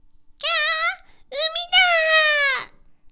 ボイス
キュート